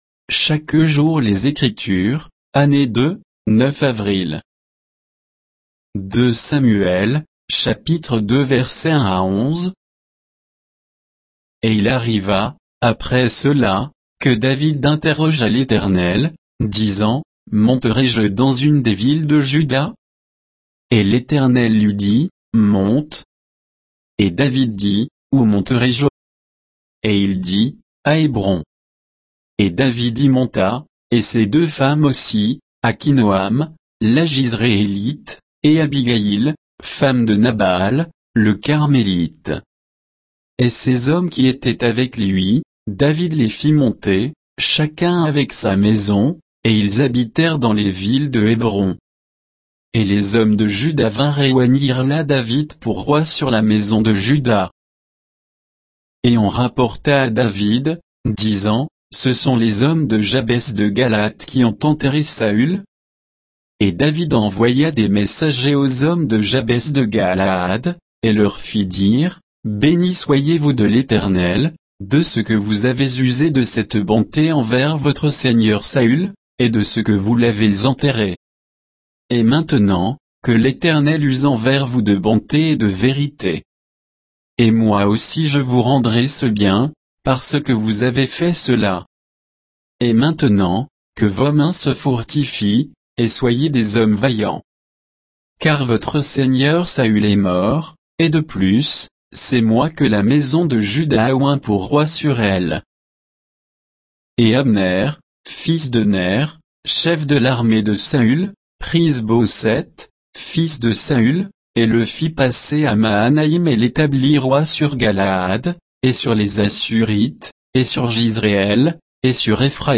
Méditation quoditienne de Chaque jour les Écritures sur 2 Samuel 2